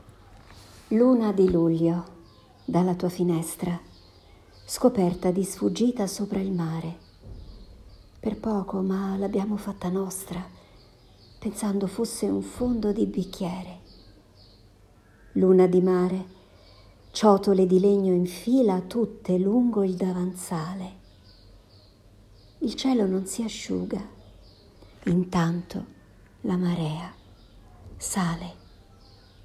La poesia che presento qui, e che leggo in audio, racchiude bene i concetti di cui sopra.